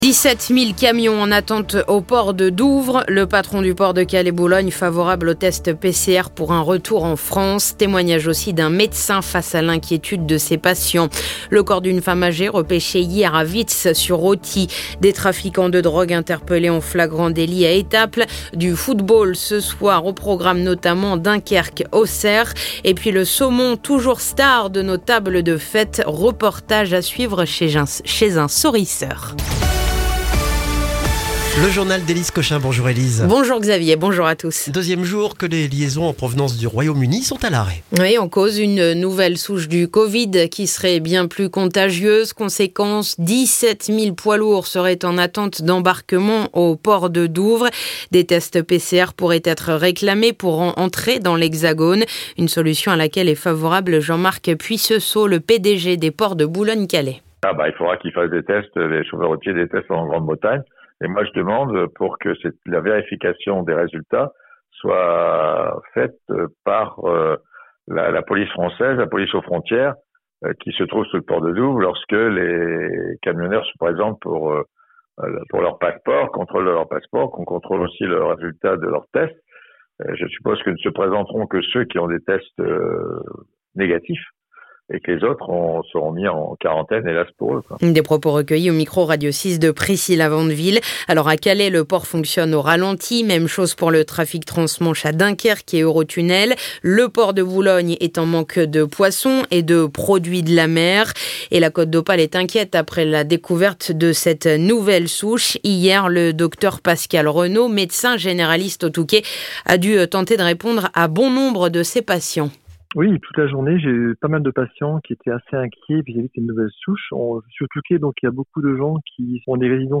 Le journal du mardi 22 décembre